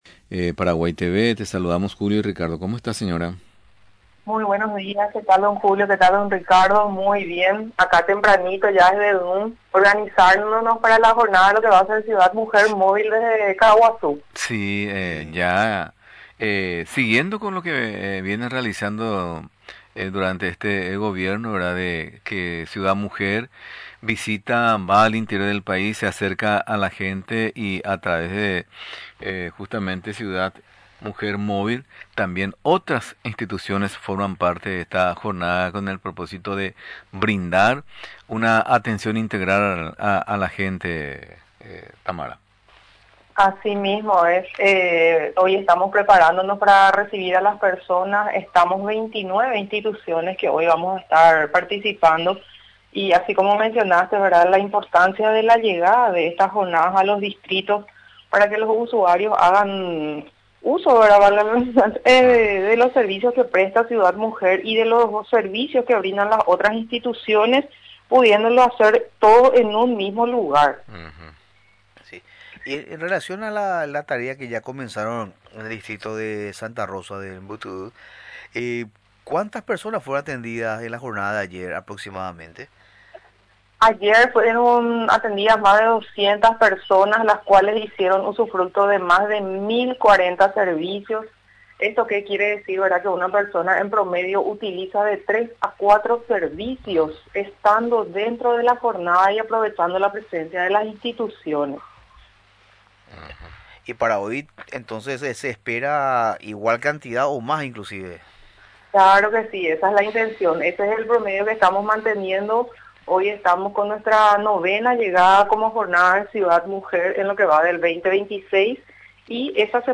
Mencionó, durante la entrevista en Radio Nacional del Paraguay, que realizaron en el distrito de Santa Rosa del Mbutuy, un total de 1.040 servicios gratuitos.